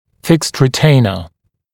[fɪkst rɪ’teɪnə][фикст ри’тэйнэ]несъемный ретейнер